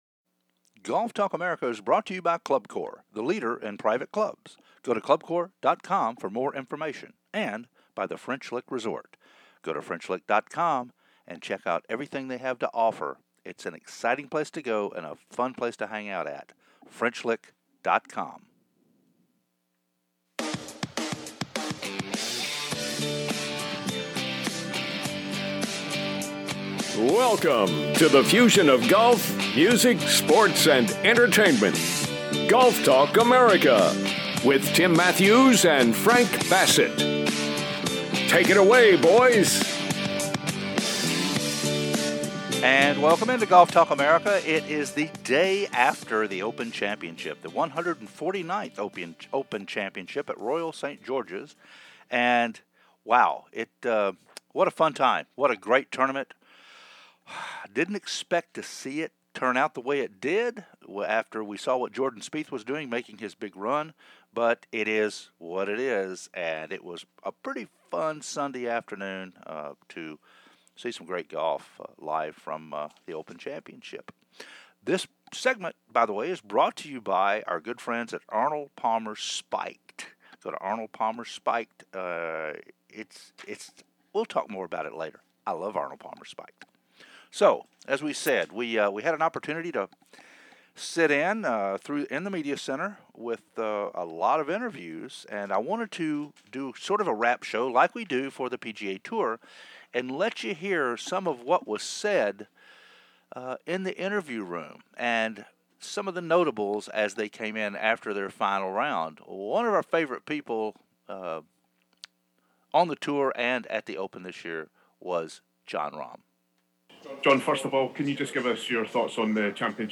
Interviews from Koepka, McIlroy, Rahm, DeChambeau, Lowery, Spieth & Morikawa as we wrap up the 149th Open Championship